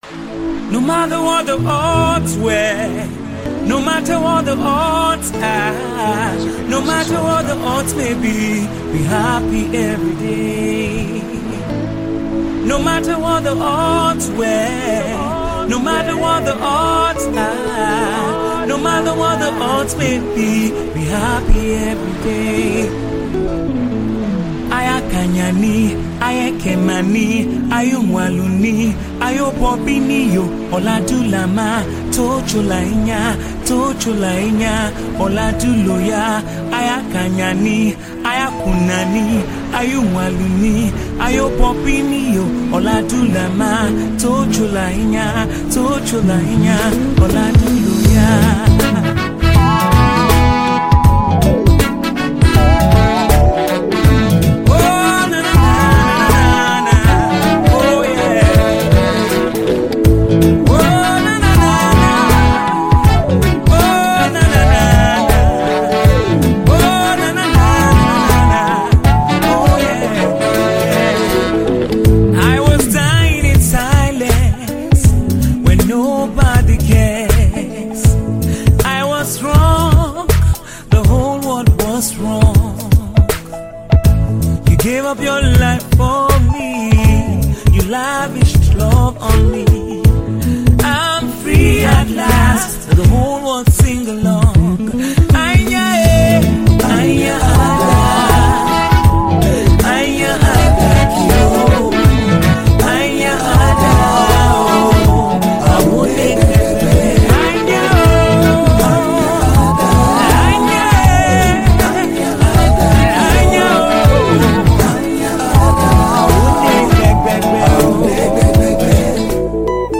Tiv Song